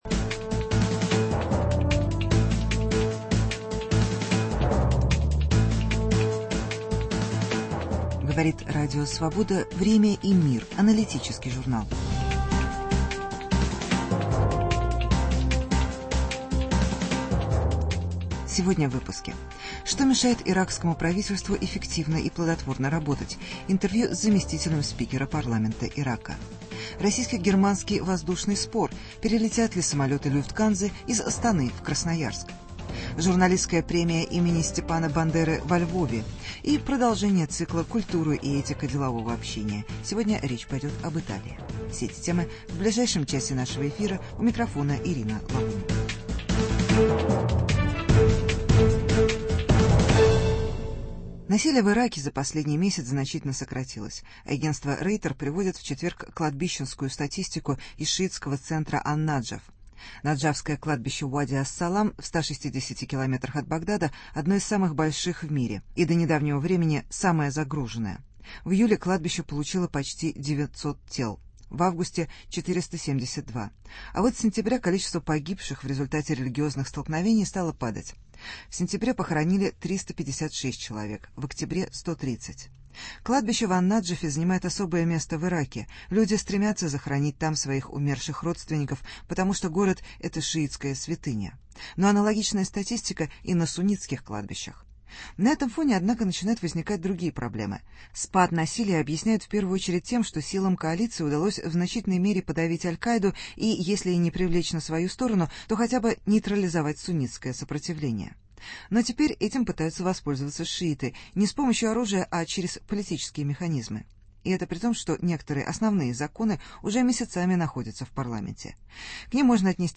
Интервью с членом иракского парламента. Российско-германский конфликт в сфере гражданской авиации. Журналистская премия имени Степана Бандеры на Украине.